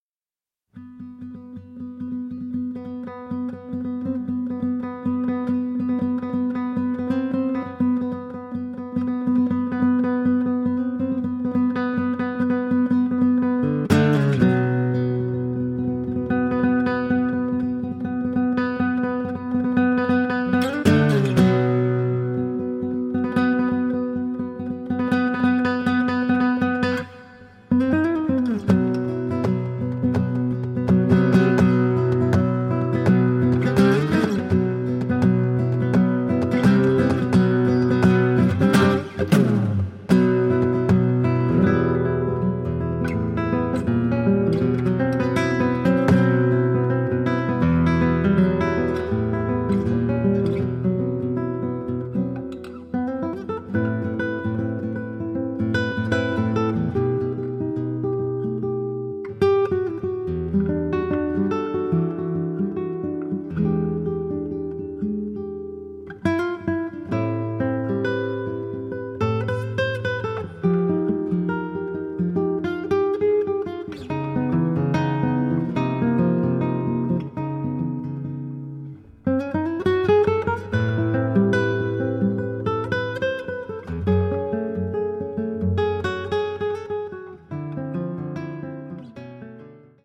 clarinet
guitar
bass